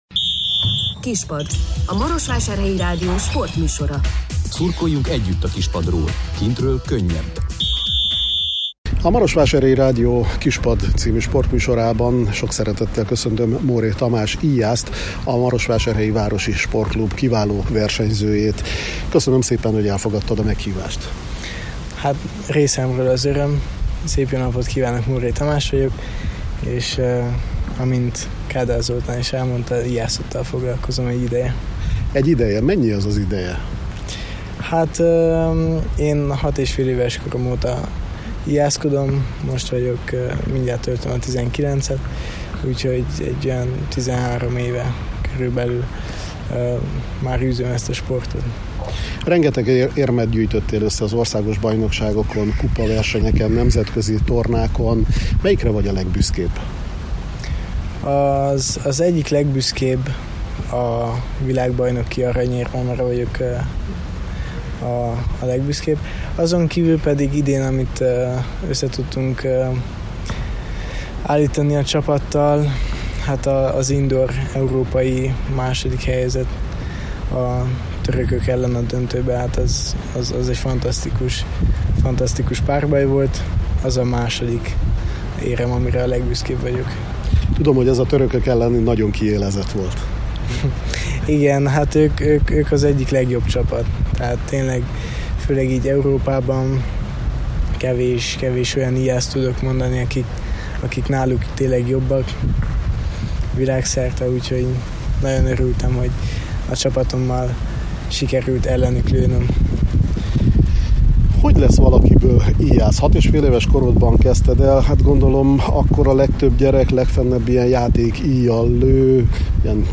beszélgetett vele a sportág szépségéről, a fizikai és mentális felkészülés fontosságáról és a maratonfutásról is: